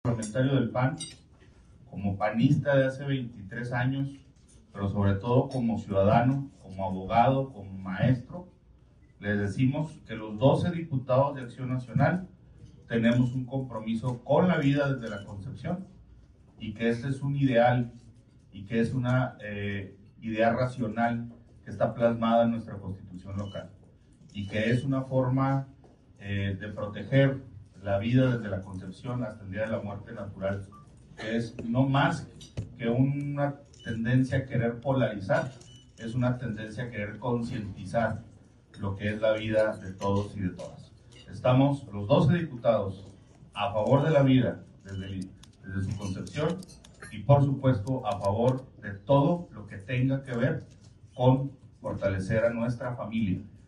Noticias Chihuahua: Noticias de Chihuahua El grupo Parlamentario del Partido Acción Nacional en el Congreso de Chihuahua, citó a rueda de prensa para fijar un posicionamiento contra la despenalización del aborto . El coordinador de la bancada Alfredo Chávez, aseguró que los 12 diputados que la integran asumieron la decisión de retomar los valores que dijo representan al PAN en favor de la vida y la familia.